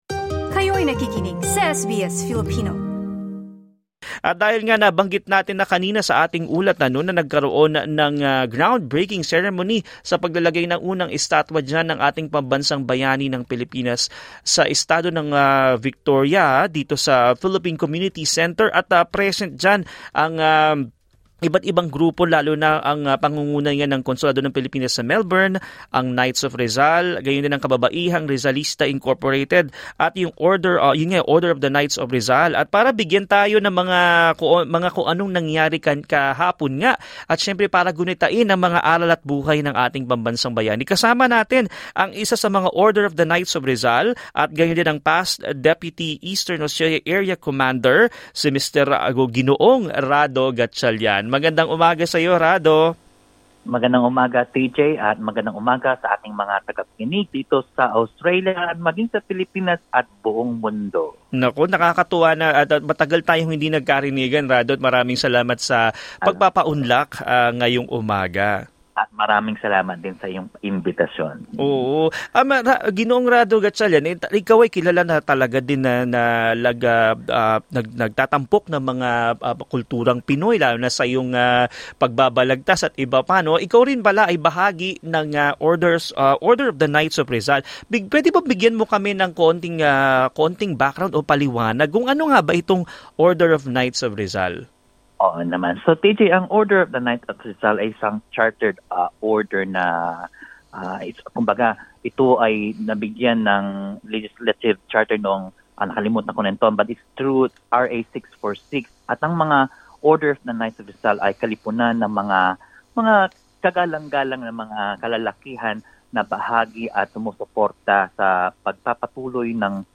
In an interview with SBS Filipino